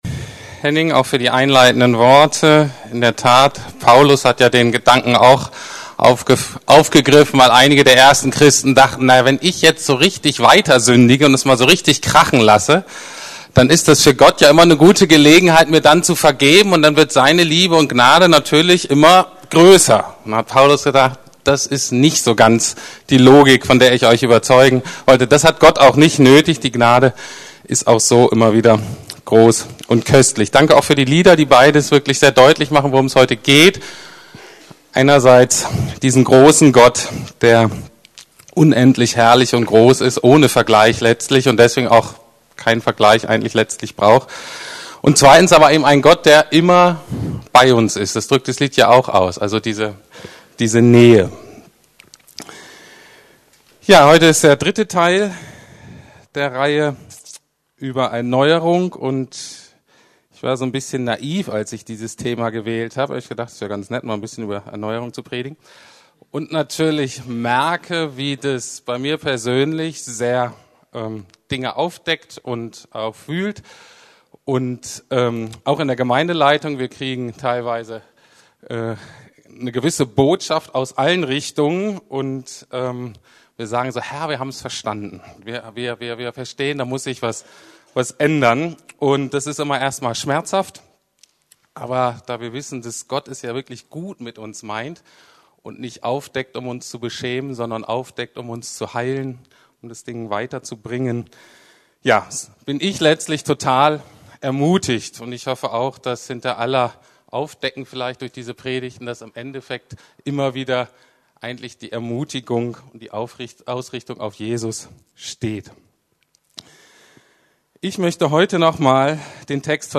Erneuerung Teil 3: Die Rache des Götzen ~ Predigten der LUKAS GEMEINDE Podcast